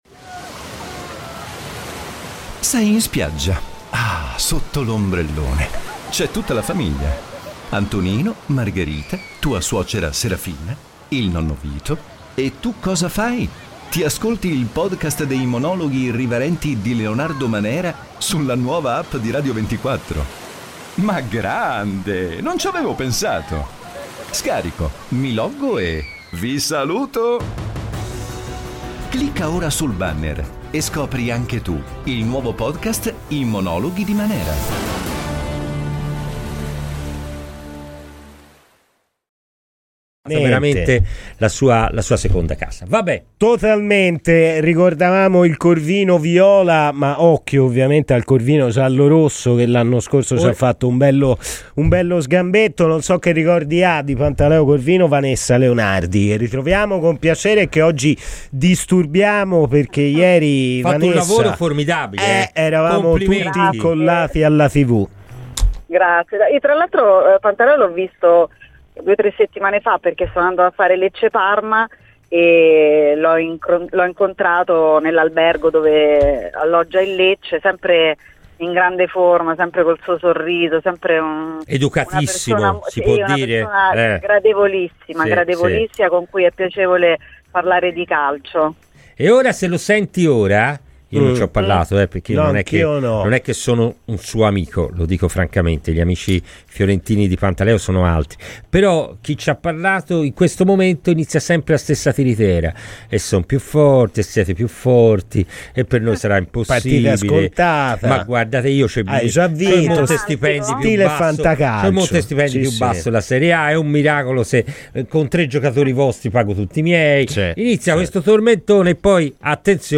è stata ospite nel pomeriggio di Radio FirenzeViola per parlare di quanto visto ieri